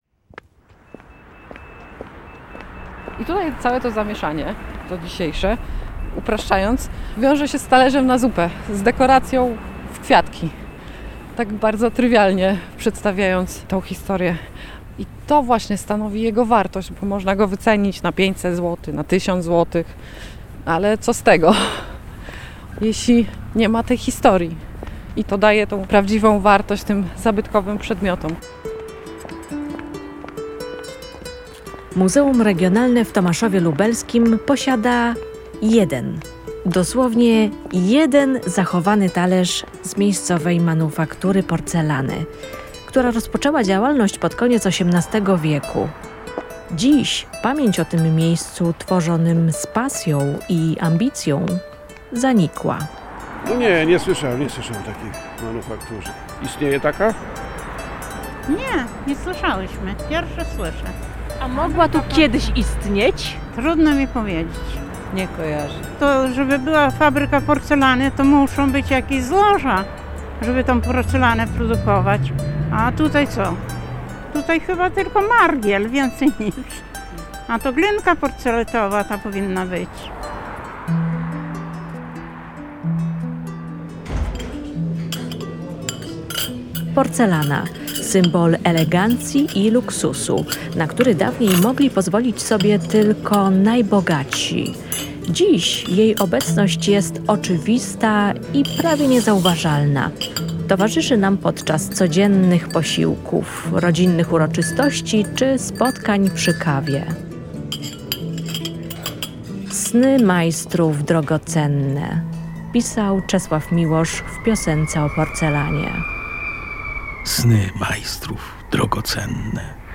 Reportaż o manufakturze fajansu i porcelany w Tomaszowie Lubelskim, założonej przez Franciszka de Mezera na zlecenie XI ordynata Aleksandra Augusta Zamoyskiego.